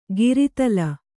♪ giri tala